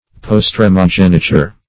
Search Result for " postremogeniture" : The Collaborative International Dictionary of English v.0.48: Postremogeniture \Pos*tre`mo*gen"i*ture\ (?; 135), n. [L. postremus last + genitura birth, geniture.] The right of the youngest born.
postremogeniture.mp3